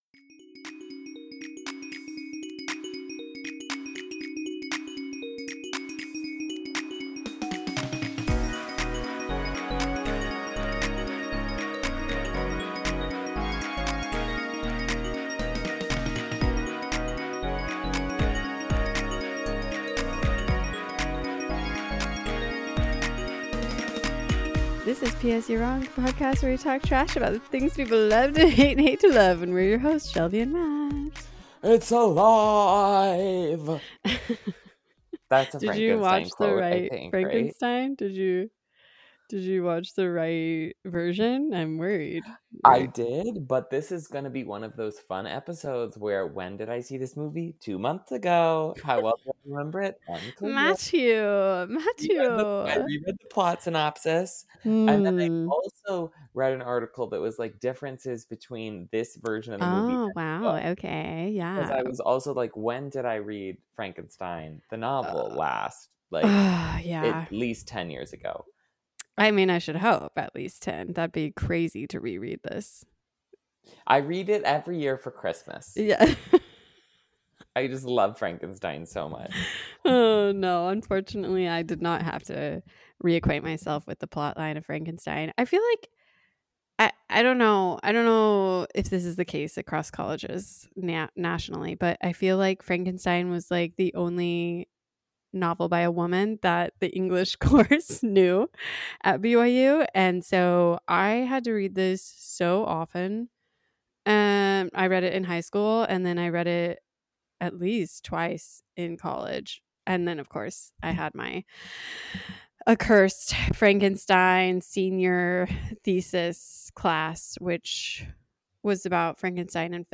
Two nerds arguing about the merits of Frankenstein?